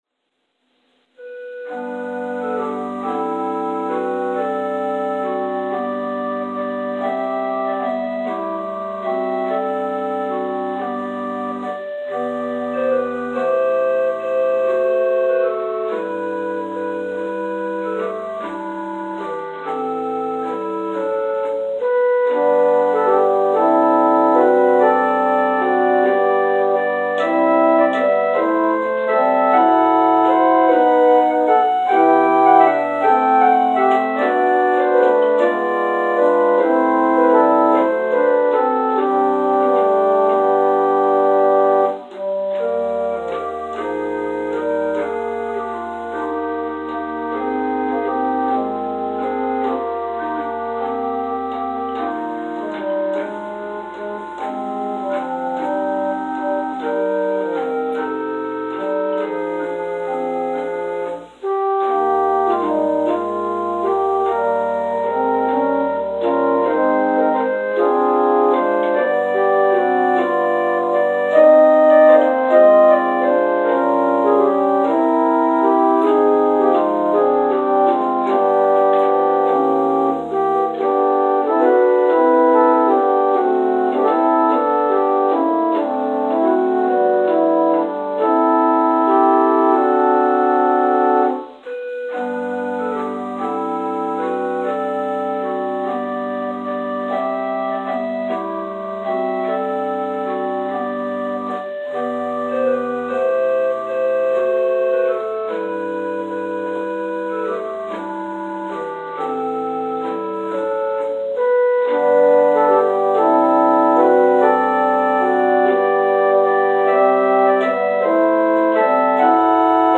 Interlude: “Peace in My Soul” – Robert J. Hughes